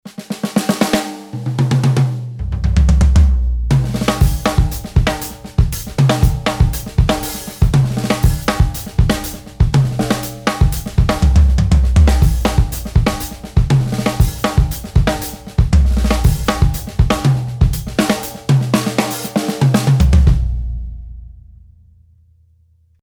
Tama Starclassic Select Walnut Drumset
Starclassic Select walnut shells are 6-ply and 5 mm for the snare and toms, while the kick is 7-ply and 6 mm.
Tama-Starclassic-Walnut-kit-pg-20.mp3